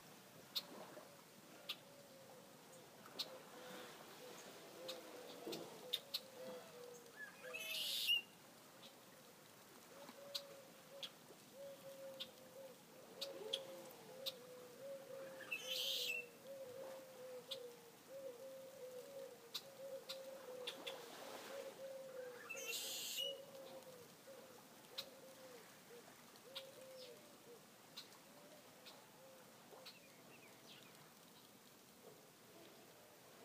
rh-red-winged-blackbird-f-abaco-bahamas-copy.m4a